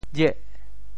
“廿”字用潮州话怎么说？
jih8.mp3